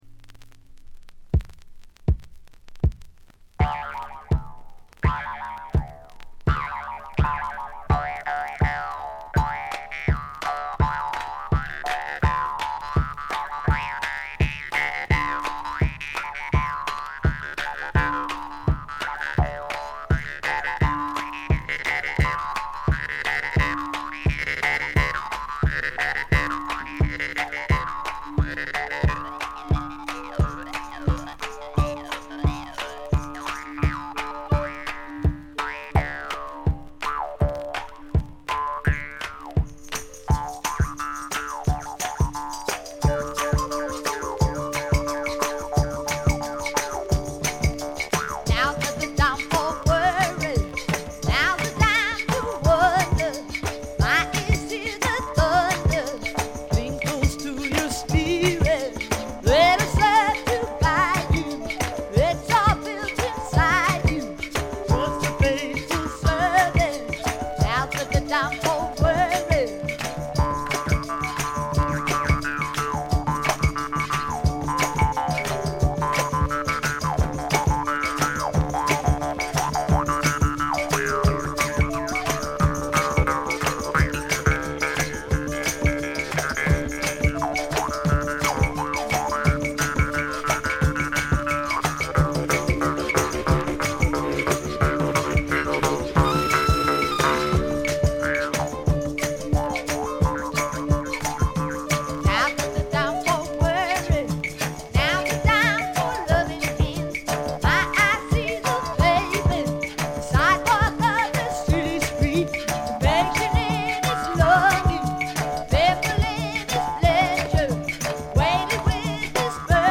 常時大きめのバックグラウンドノイズが出ていますが、特に気になるようなノイズはありません。
スワンプナンバーでは強靭な喉を聴かせますが、アシッド路線では暗く妖艶で怪しいヴォーカルを響かせます。
試聴曲は現品からの取り込み音源です。